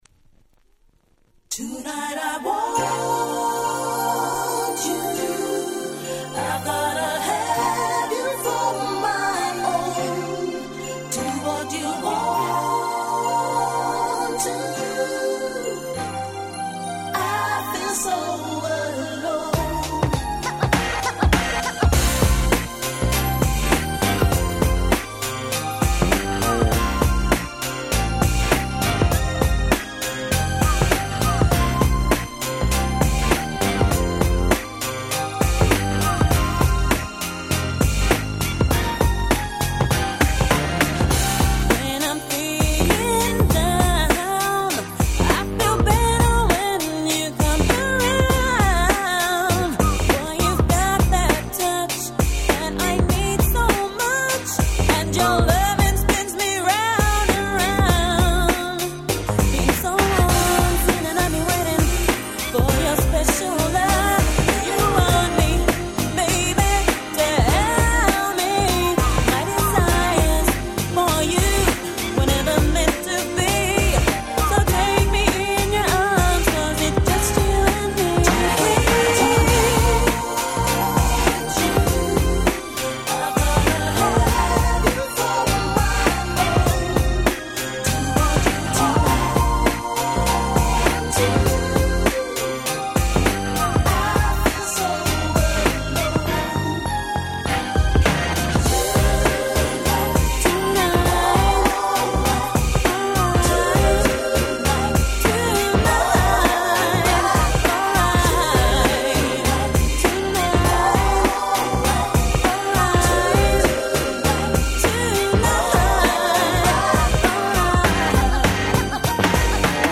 Nice UK R&B !!!
哀愁漂うGroovyでSmoothな素晴らしい1曲。